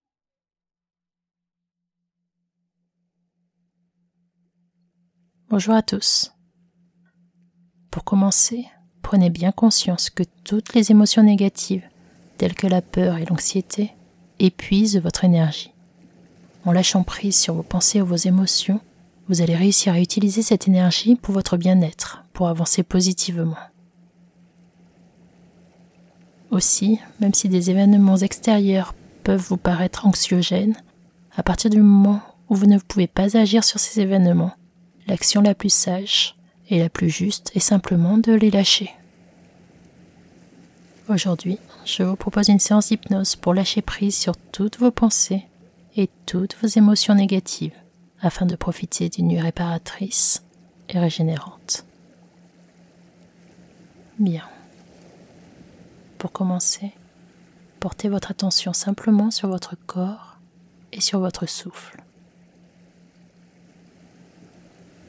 Hypnose pour dormir | Sommeil profond et régénérant - Bulles de Légèreté
extrait-hypnose-pour-dormir-sommeil-profond-et-regenerant.mp3